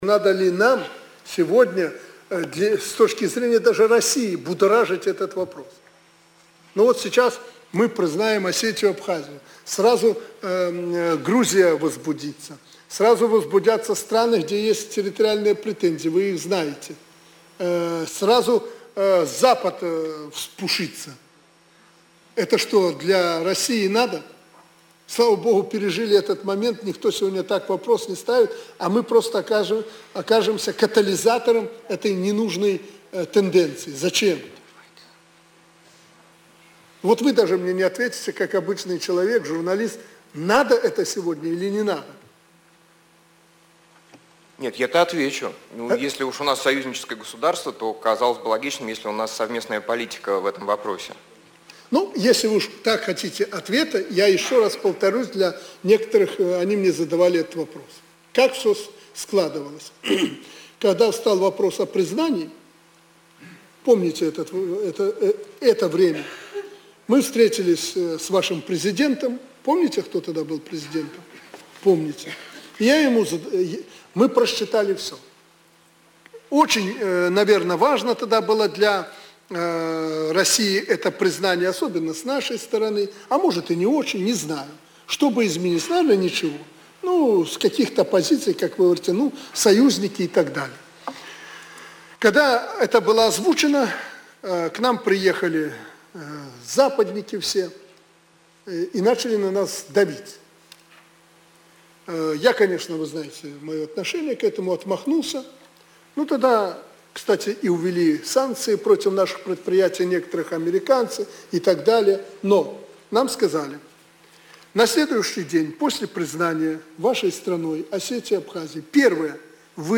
Прэсавая канфэрэнцыя Аляксандра Лукашэнкі для расейскіх журналістаў. Менск, 11 кастрычніка 2013